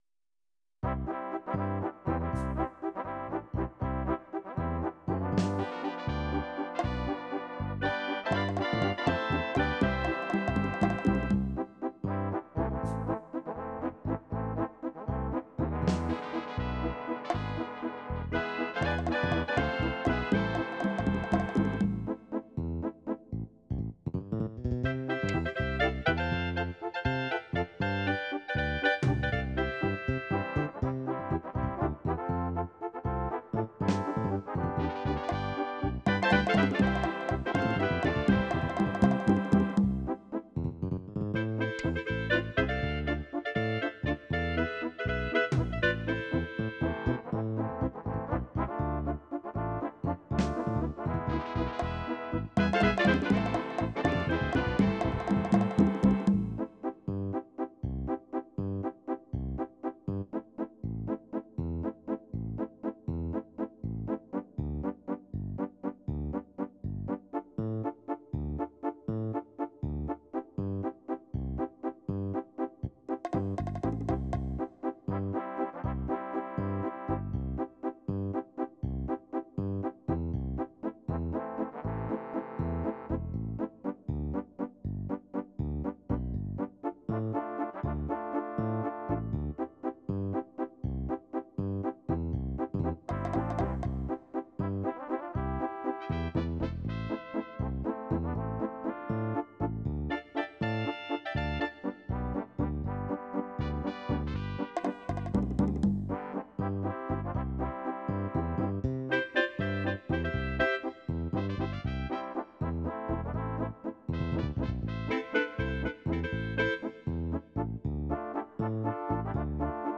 17 styles / estilos / ritmos bandana mexicana